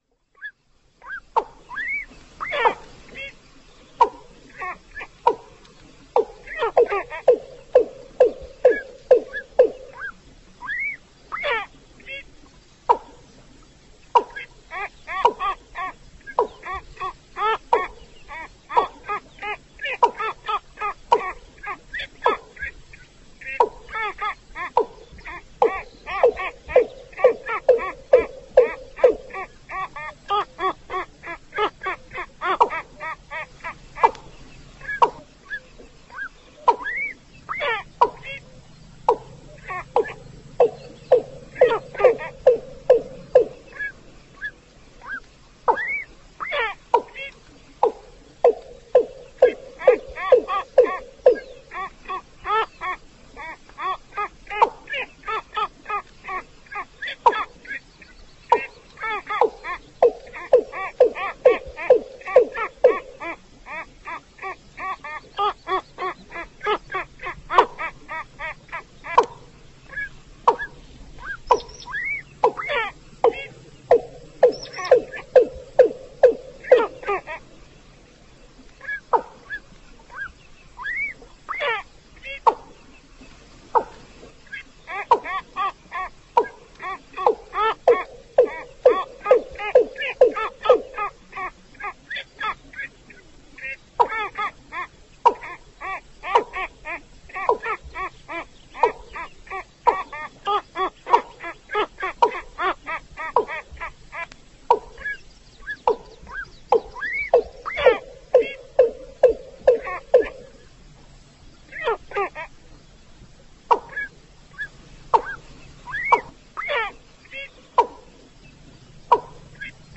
Bạn có thể tải tiếng gà nước mái kêu MP3 với âm thanh to, rõ ràng, chuẩn nhất.
Tiếng Gà Nước mái kêu
Chủ đề: đồng ruộng/ đầm lầy tiếng Cúm Núm tiếng gà đồng tiếng gà nước
tieng-ga-nuoc-mai-keu-www_tiengdong_com.mp3